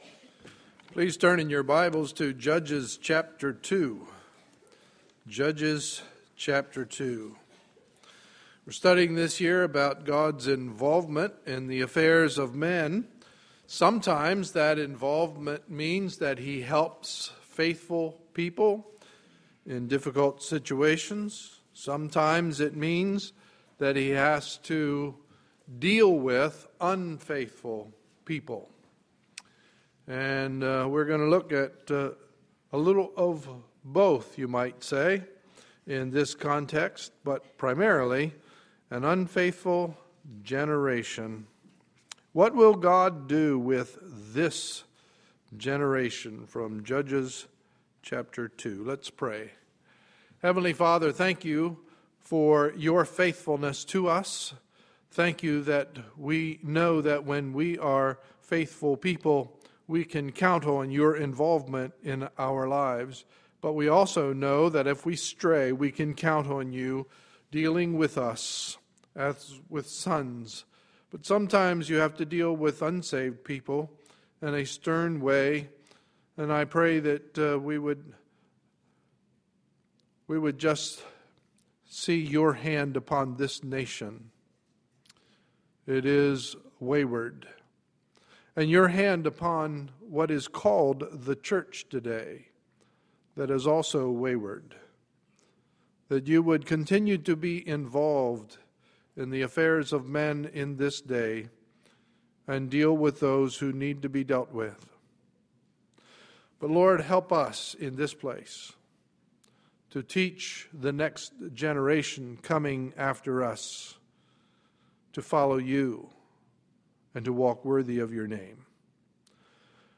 Sunday, July 7, 2013 – Morning Service